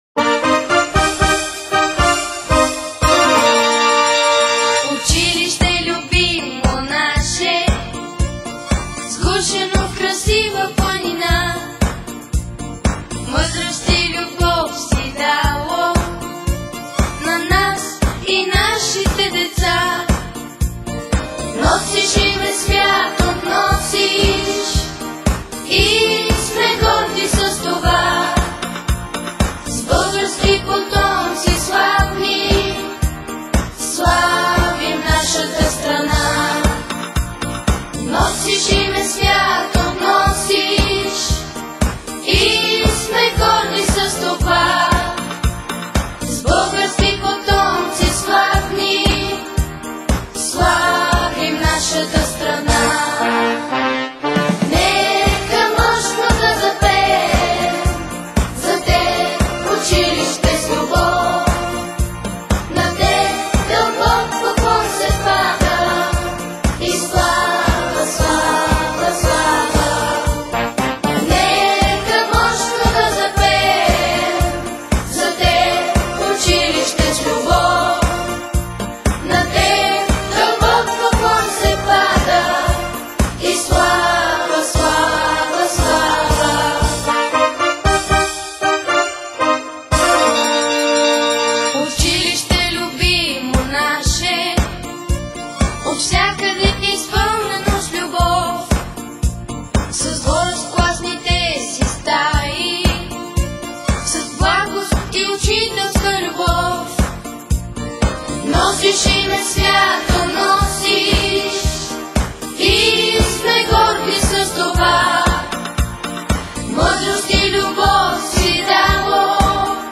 Химн